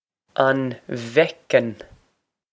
On vec-n
This is an approximate phonetic pronunciation of the phrase.